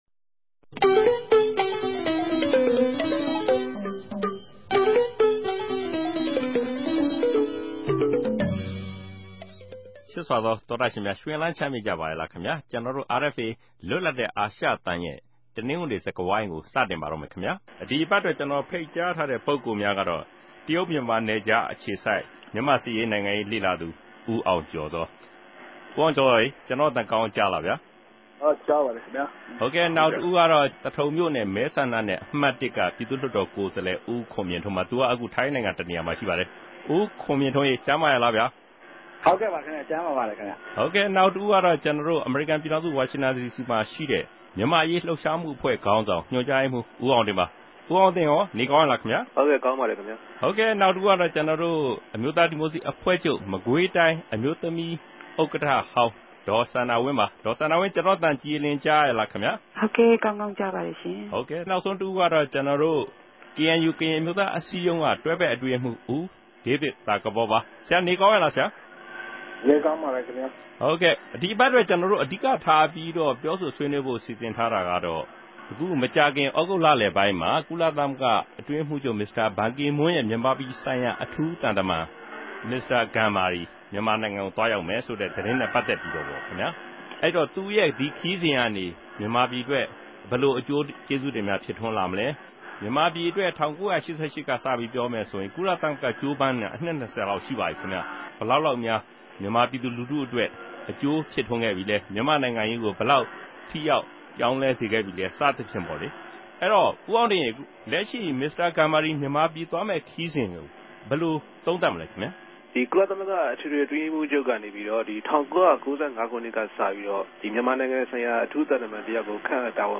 တနဂဿေိံစြကားဝိုင်း။